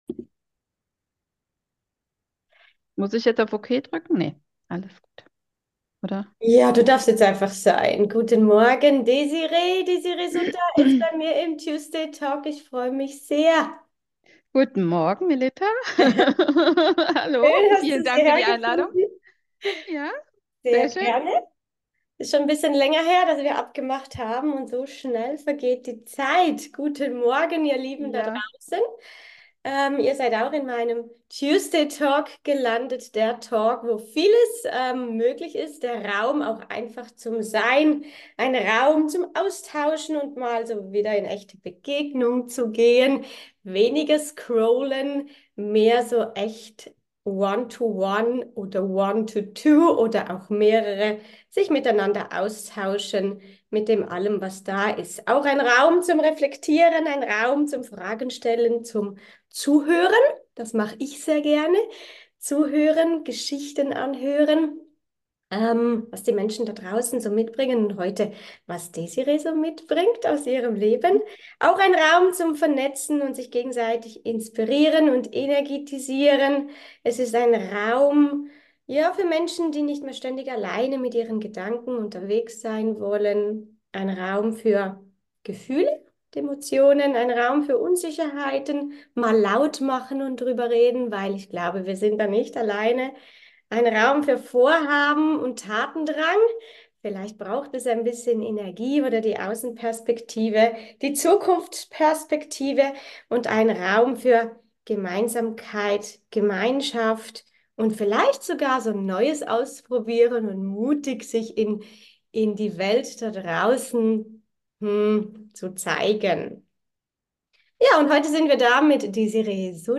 Hier ist alles ECHT - kein Filter, kein Schnitt, kein irgendwas. So wie wir miteinander gesprochen haben, so hörst du hier rein - als ob du live dabei wärst.
Es kann passieren, dass mal die Technik ihren Eigenlauf nimmt.